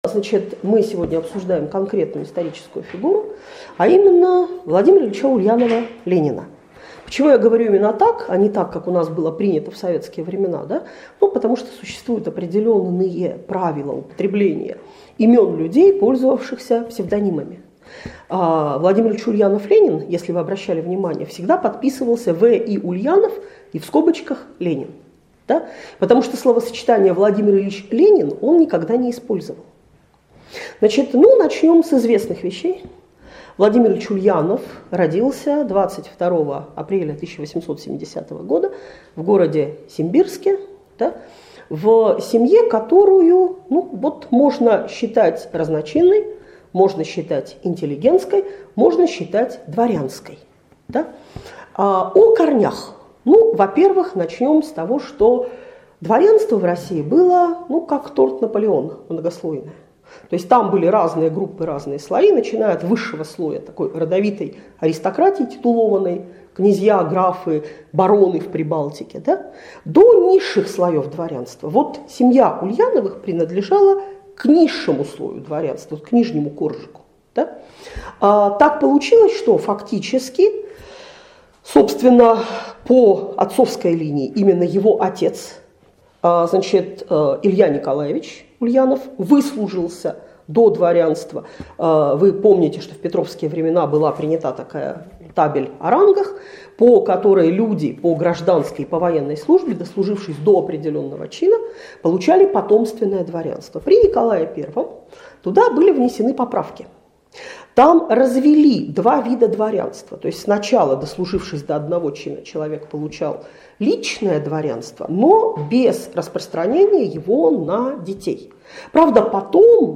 Лекция о жизни и деятельности В. И. Ленина. ◽ К сожалению, в Советское время образ Ленина был чрезмерно идеализирован и лишен живого, человеческого содержания.